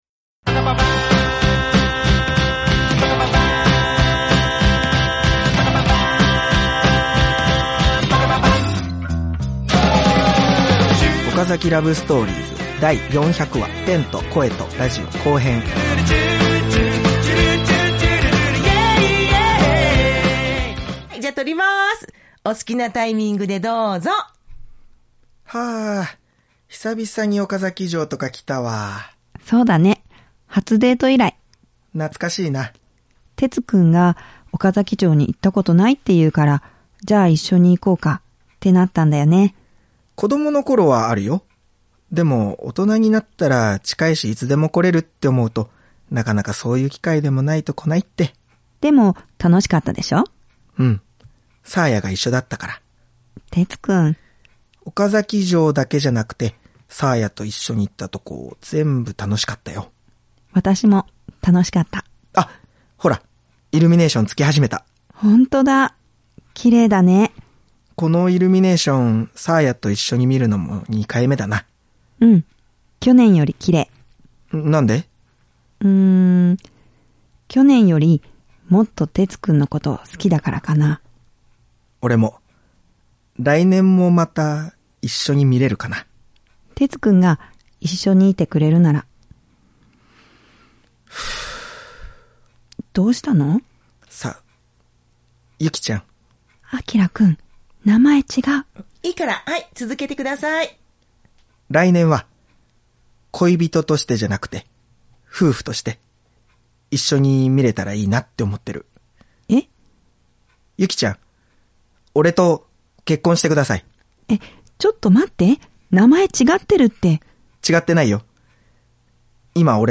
身近でリアルな恋愛をドラマにしてお送りする「OKAZAKI LOVE STORIES」。